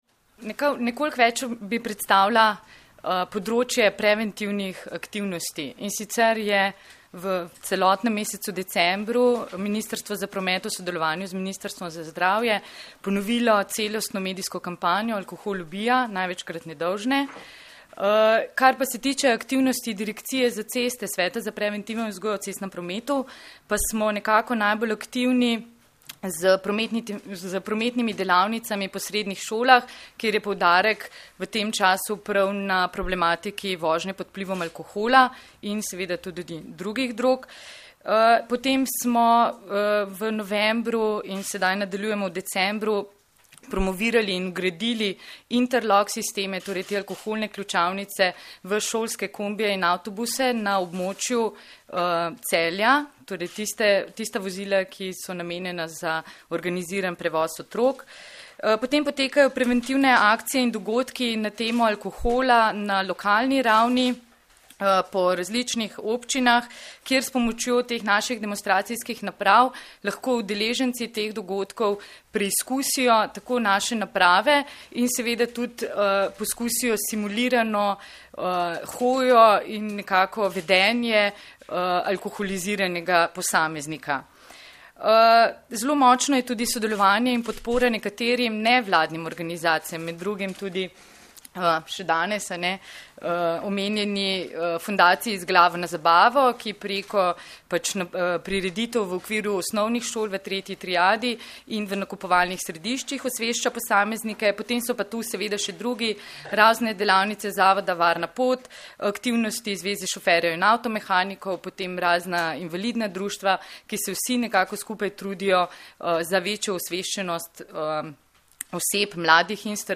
Policija - Alkohol in vožnja v cestnem prometu nista združljiva - informacija z novinarske konference